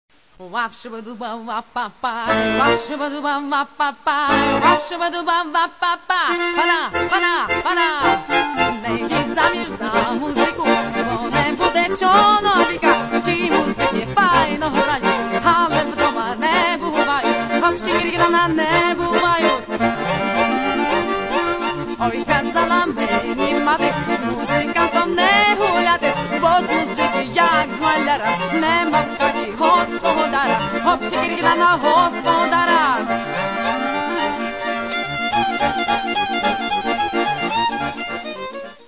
Каталог -> Поп (Легка) -> Етно-поп